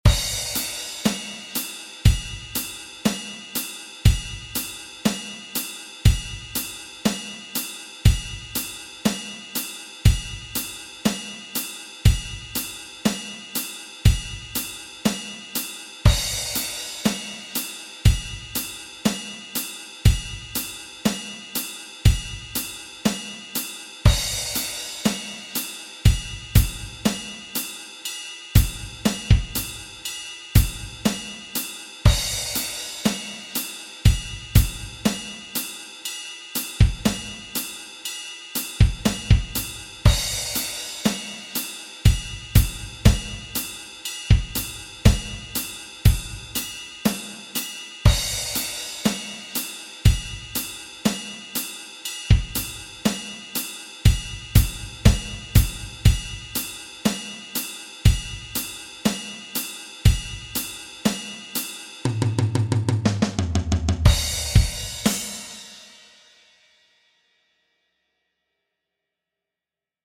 Die Bass Drum wird anschließend verändert.
Unabhängigkeitsübungen langsam.mp3
unabhangigkeitsubungen_langsam.mp3